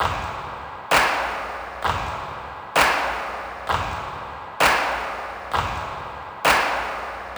CLAP_ROCKSTAR.wav